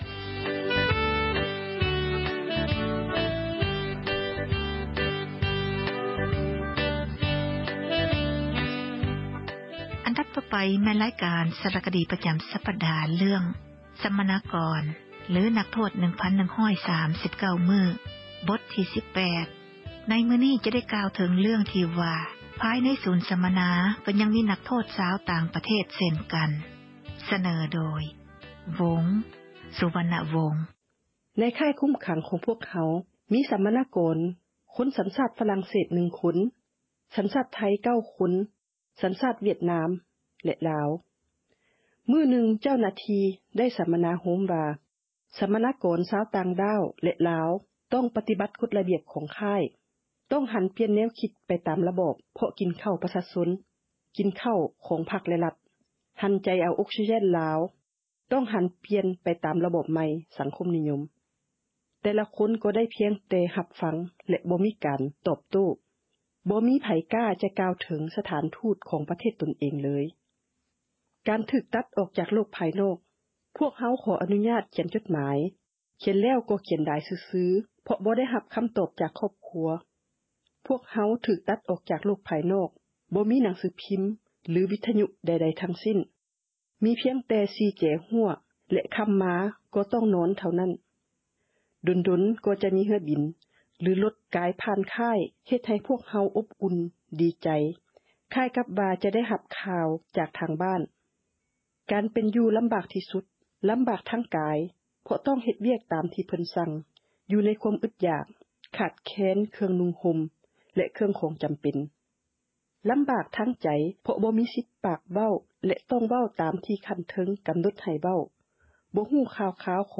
ສາຣະຄະດີ ເຣື້ອງ ສັມມະນາກອນ ຫຼື ນັກໂທດ 1139 ມື້, ບົດທີ 18 ຈະກ່າວເຖິງ ເຣື້ອງ ພາຍໃນສູນ ສັມມະນາ ກໍຍັງມີ ນັກໂທດ ຊາວ ຕ່າງປະເທດ ເຊັ່ນກັນ. ສເນີໂດຍ